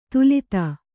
♪ tulita